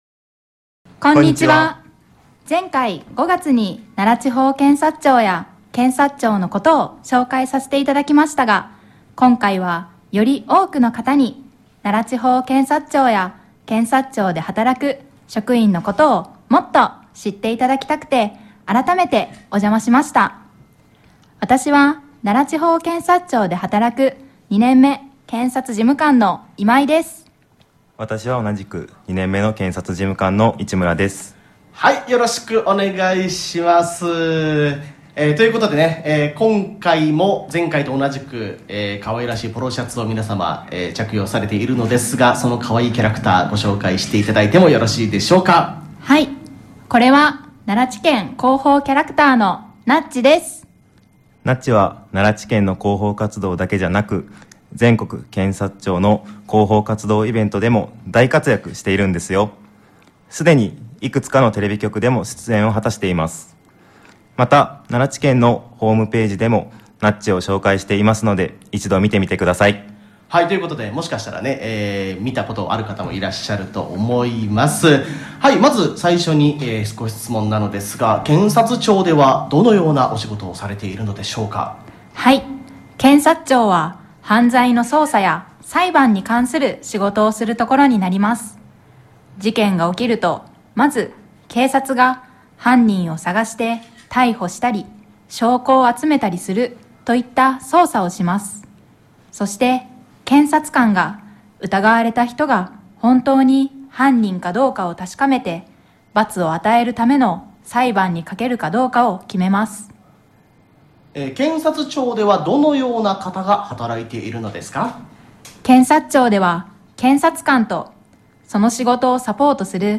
令和７年１０月１８日の午後０時１５分頃から午後０時３０分頃までの間、ならどっと ＦＭで放送されている「ひるラジ！サタデー」に、奈良地検職員３名が出演しました！！ 放送では、検察庁の仕事の内容、検察官及び検察事務官の仕事のやりがいや奈良地検の 業務説明会の紹介などをさせていただきました。